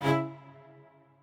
admin-leaf-alice-in-misanthrope/strings34_4_002.ogg at main